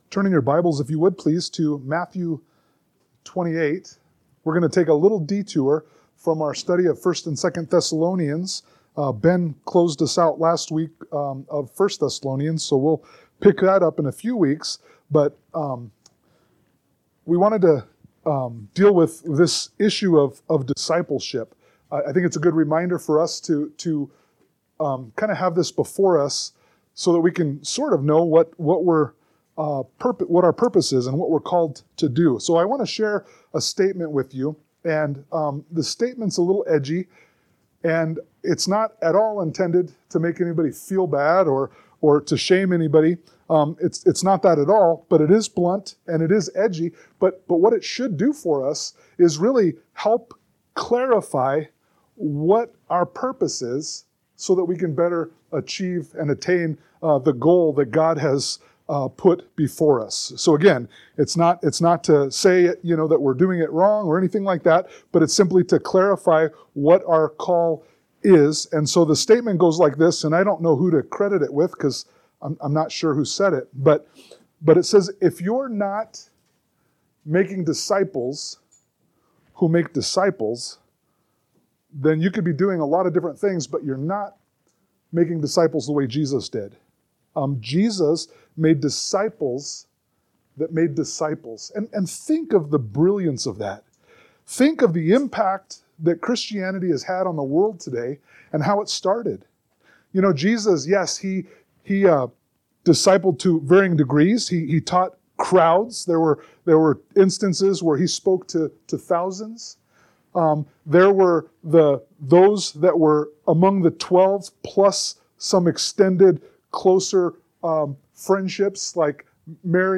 Mathew 28:16-20 Service Type: Sunday Morning Worship Download Files Notes Topics